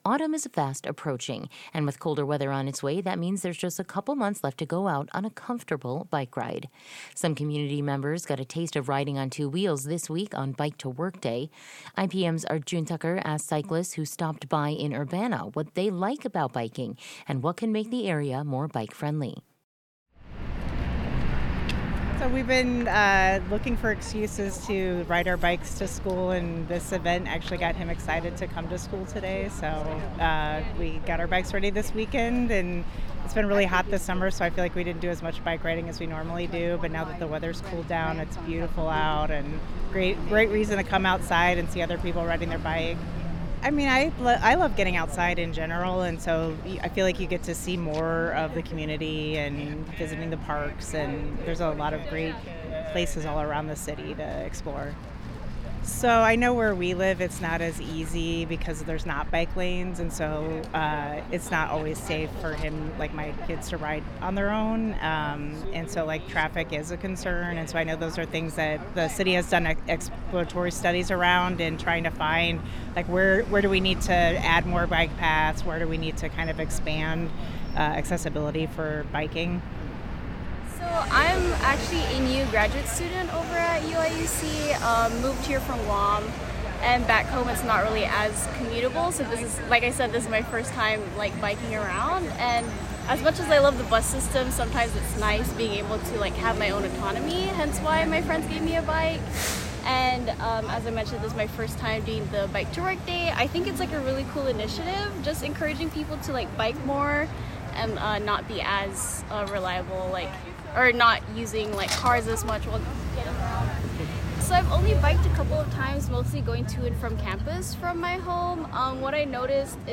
Biking-Audio-Postcard.mp3